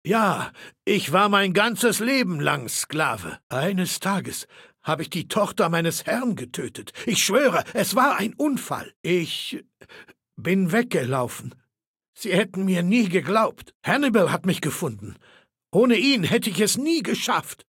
Datei:Maleold01 ms06 ms06slavestory 0005a208.ogg
Fallout 3: Audiodialoge